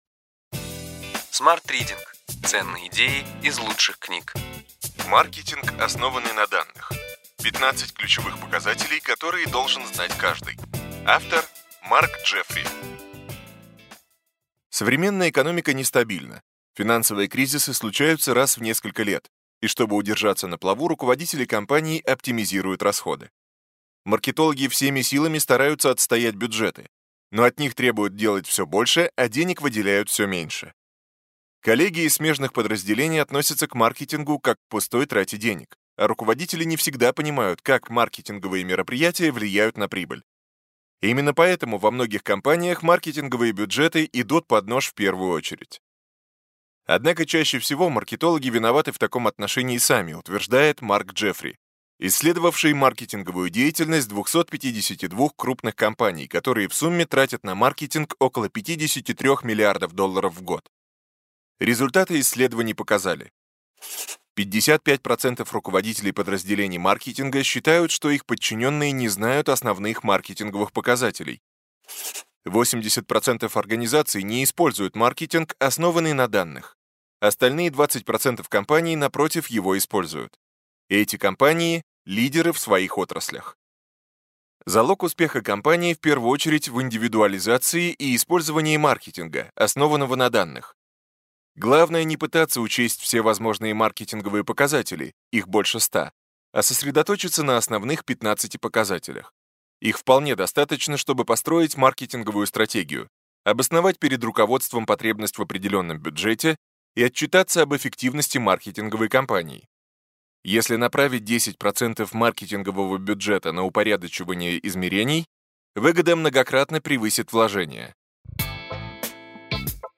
Аудиокнига Ключевые идеи книги: Маркетинг, основанный на данных. 15 ключевых показателей, которые должен знать каждый.